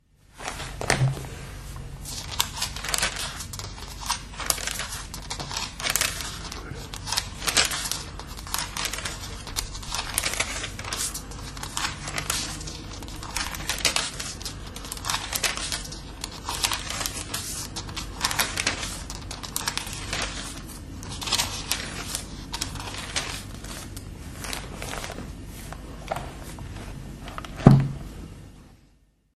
描述：翻开教会在1942年送给我父亲的《圣经》（荷兰语译本）中的《撒母耳记》第二章的书页。